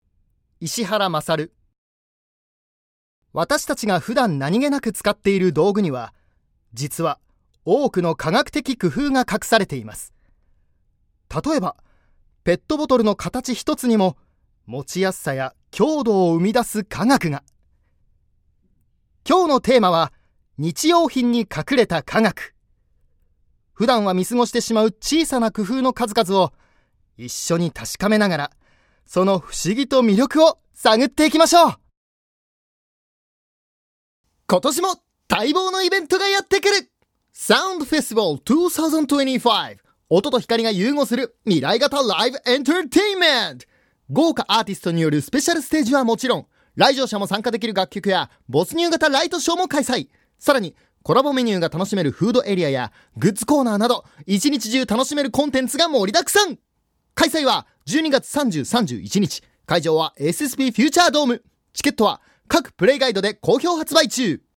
◆ナレーション